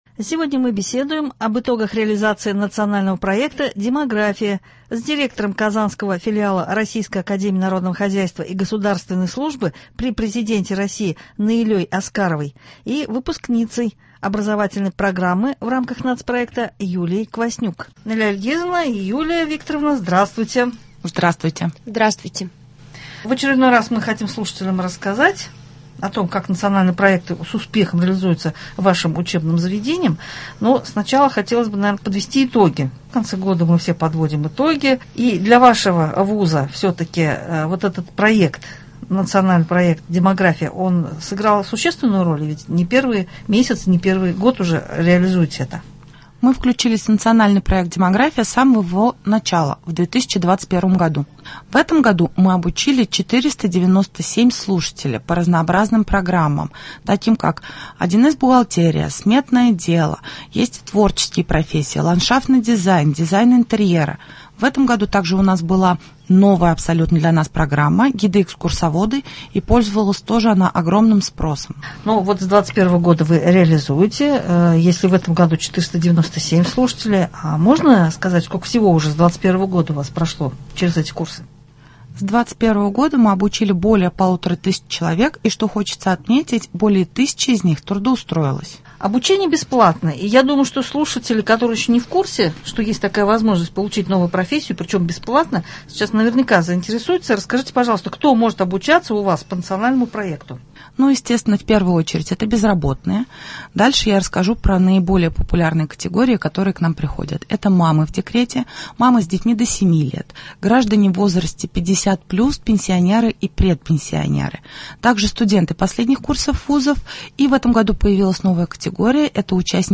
Сегодня мы беседует об итогах реализации национального проекта «Демография»